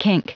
Prononciation du mot kink en anglais (fichier audio)
Prononciation du mot : kink